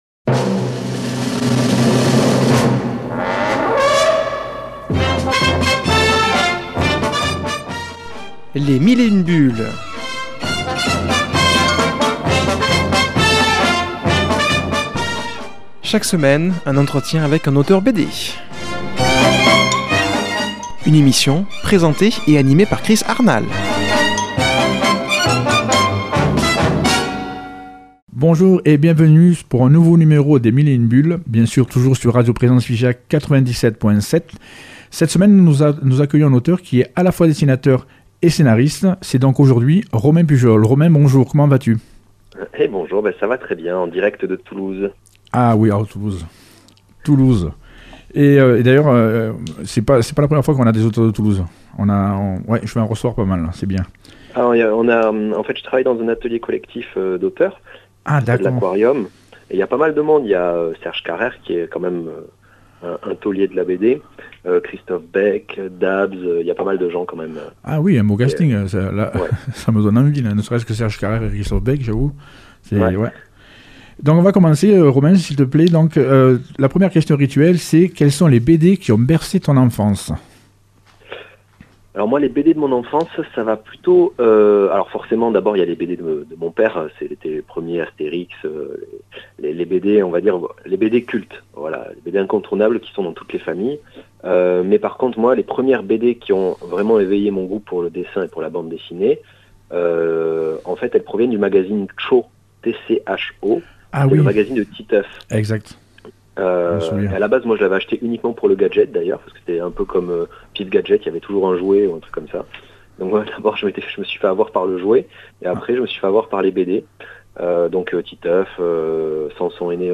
invitée au téléphone